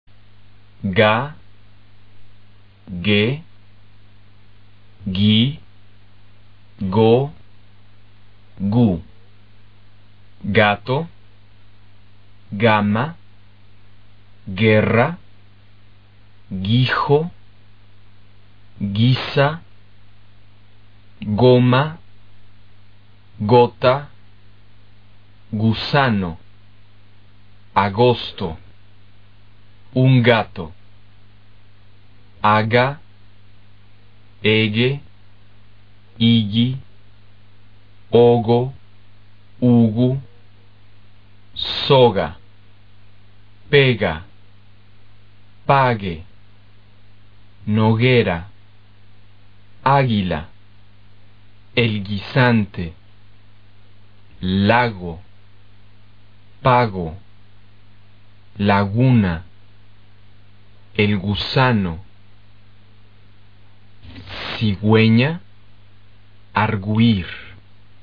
G的发音：
G在停顿后的词首，或词中、词组内N之后发【g】音。